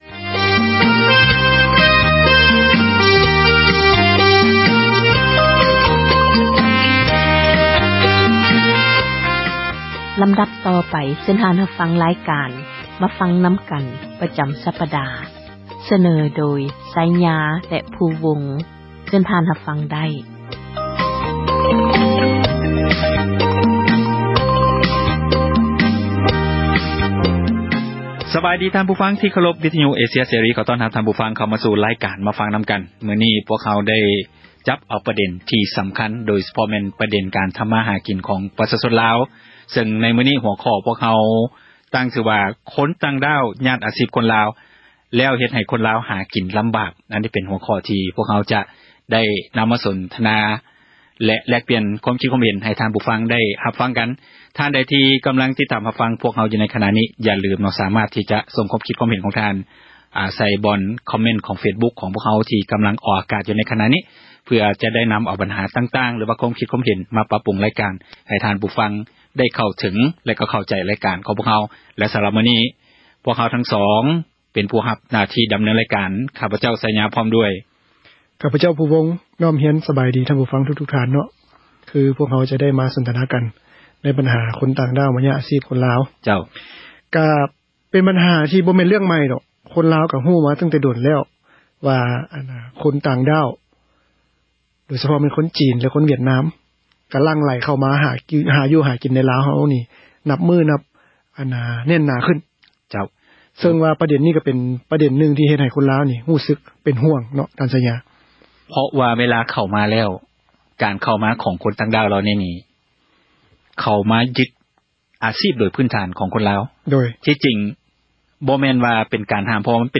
"ມາຟັງນຳກັນ" ແມ່ນຣາຍການສົນທະນາ ບັນຫາສັງຄົມ ທີ່ຕ້ອງການ ພາກສ່ວນກ່ຽວຂ້ອງ ເອົາໃຈໃສ່ແກ້ໄຂ,